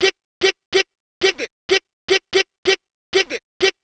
cch_vocal_loop_giveit_125.wav